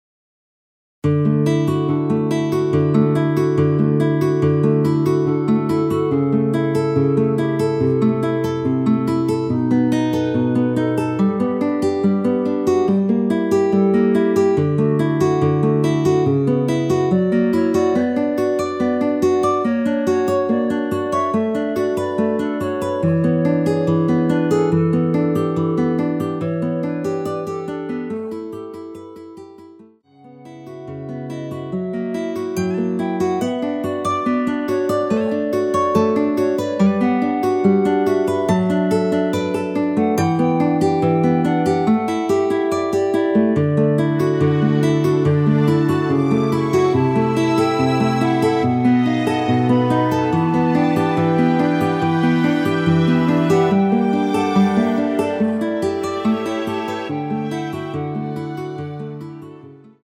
원키에서(+5)올린 멜로디 포함된 1절후 바로 후렴으로 진행 됩니다.(본문 가사및 미리듣기 확인)
멜로디 MR이라고 합니다.
앞부분30초, 뒷부분30초씩 편집해서 올려 드리고 있습니다.
중간에 음이 끈어지고 다시 나오는 이유는